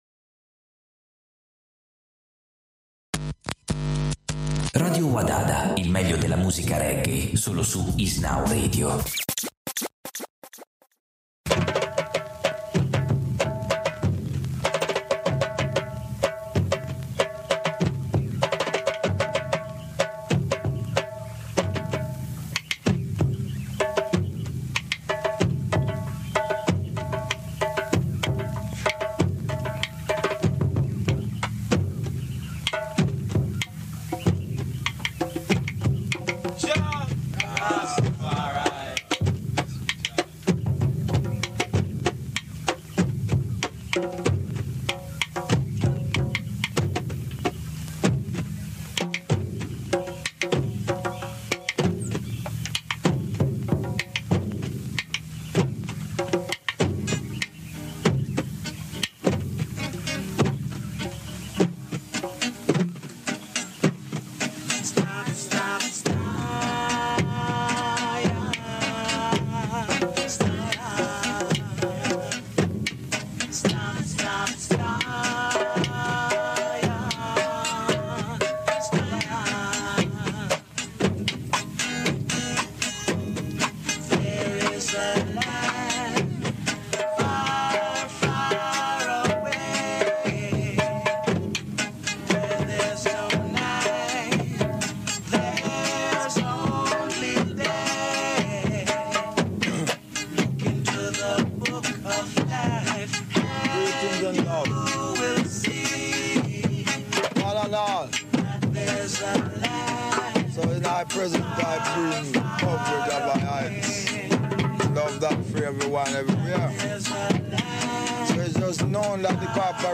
Roots Reggae Dub Music